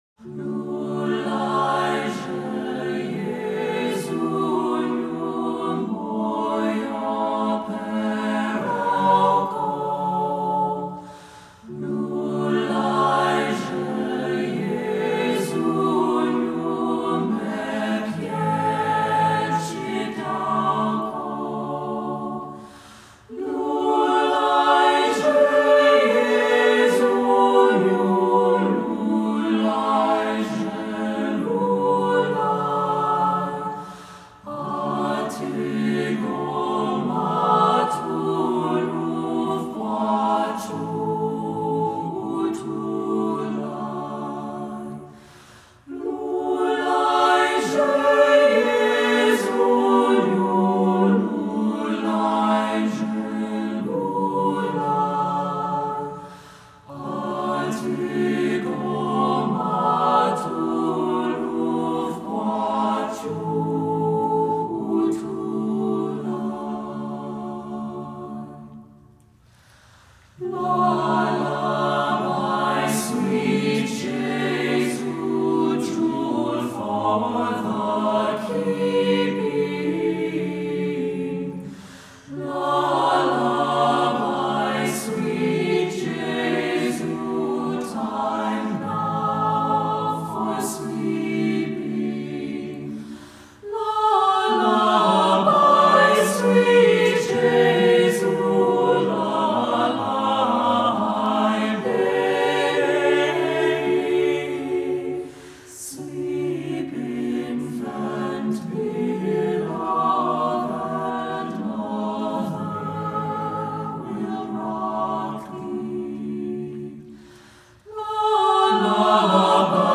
Voicing: SATB, Descant